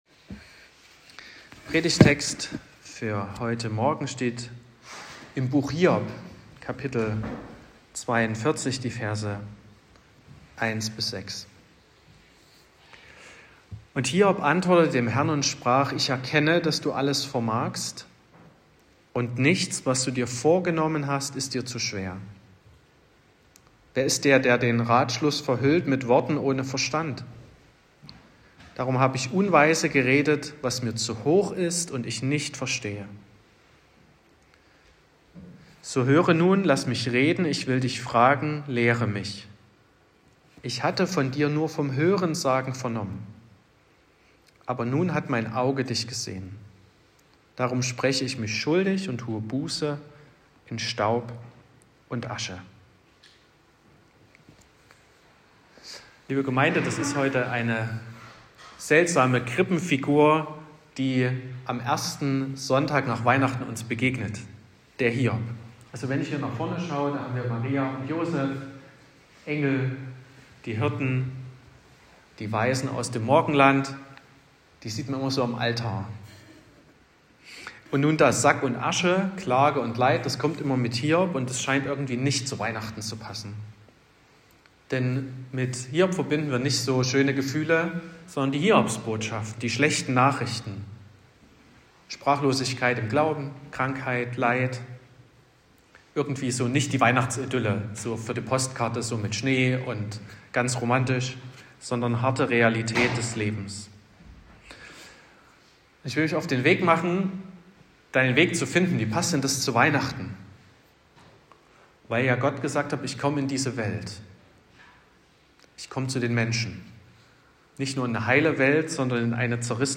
28.12.2025 – Gottesdienst
Predigt und Aufzeichnungen
Predigt (Audio): 2025-12-28_Weihnachten_mit_Hiob_-_Vom_Hoerensagen_zum_Sehen.m4a (9,6 MB)